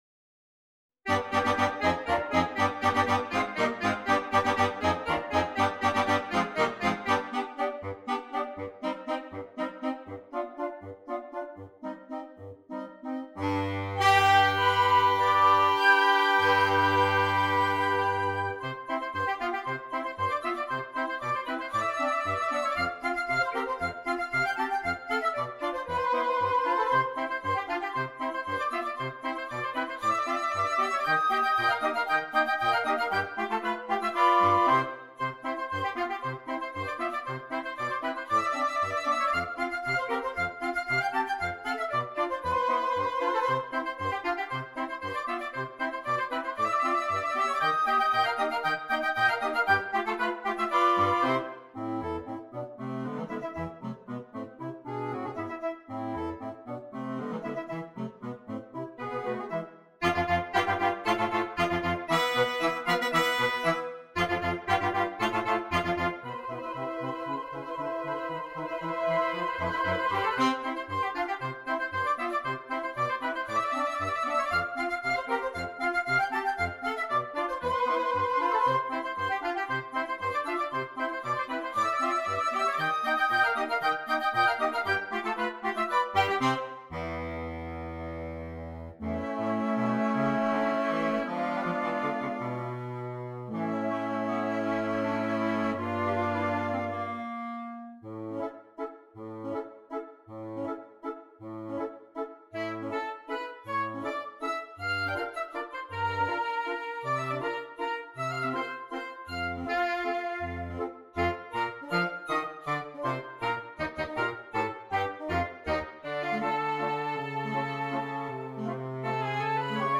Gattung: Für gemischtes Holzbläserensemble
Besetzung: Ensemblemusik für Holzbläser variable Besetzung